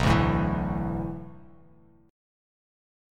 Bm11 Chord
Listen to Bm11 strummed